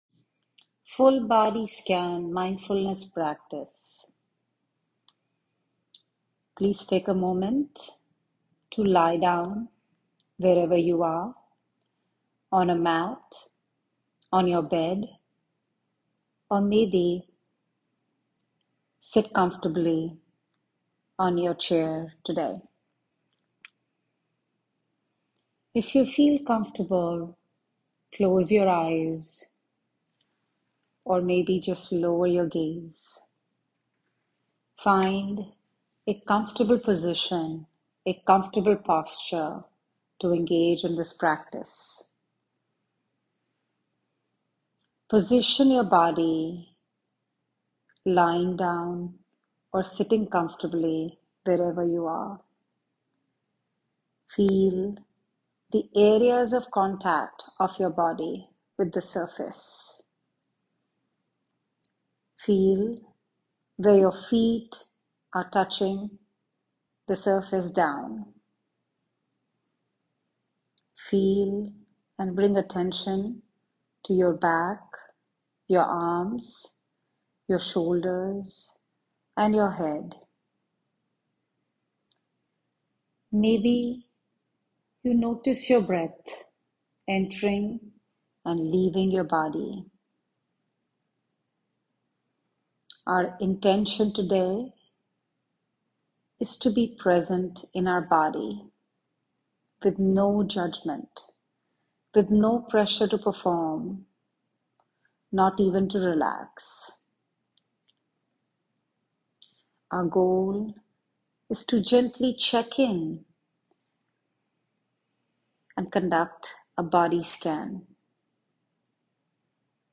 Full Body Scan Mindfulness
Full-Body-Scan-Mindfulness.m4a